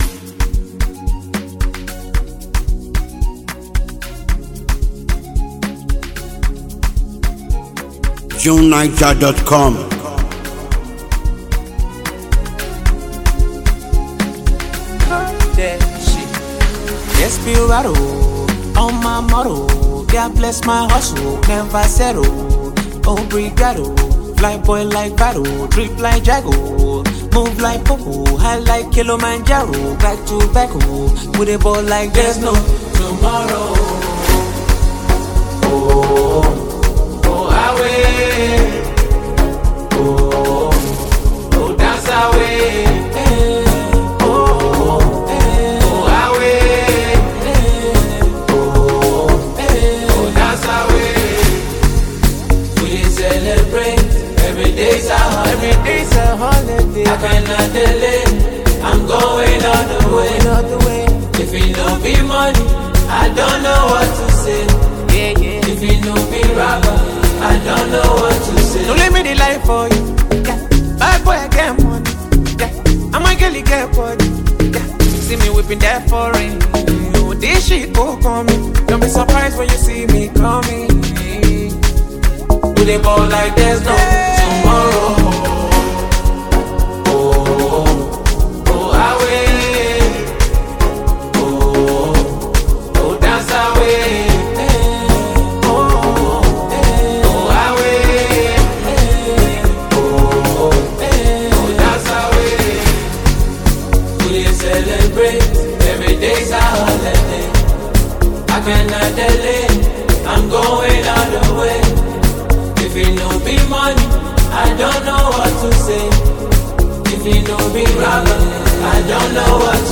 a versatile Nigerian rapper and vocalist